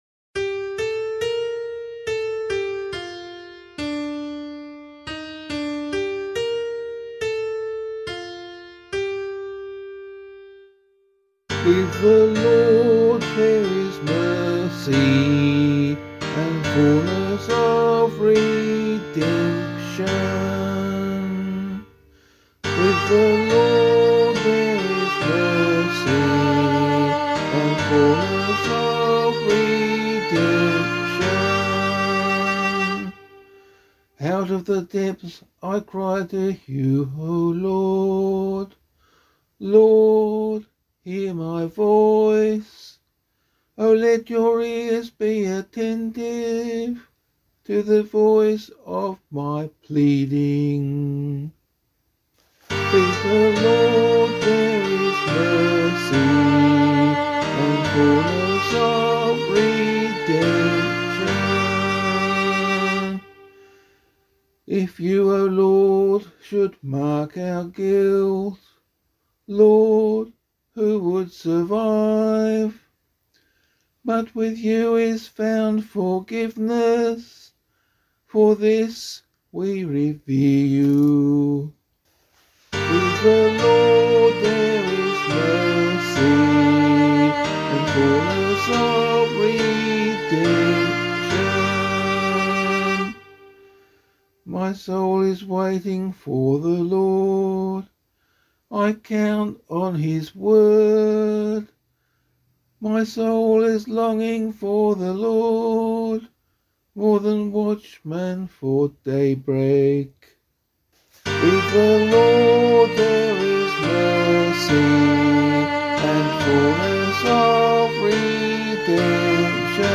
pianovocal